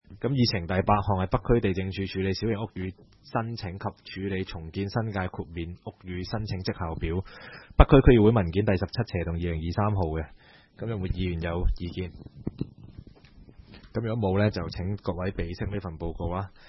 区议会大会的录音记录
北区民政事务处会议室